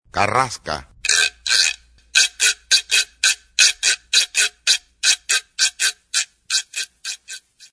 Sonidos de instrumentos Afroperuanos
"Carrasca"
carrasca.mp3